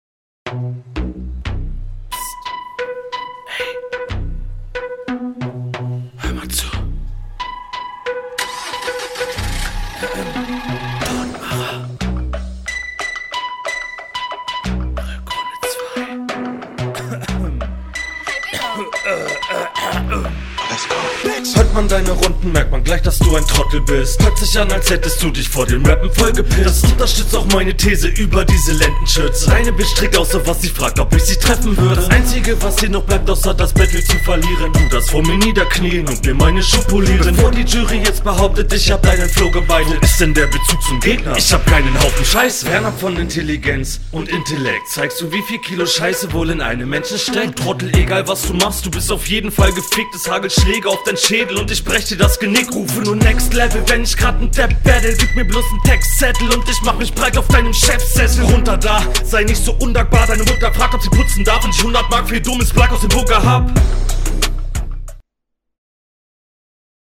Dein Flow geht deutlich mehr nach vorne. Hier ist die fehlende Flüßigkeit am start.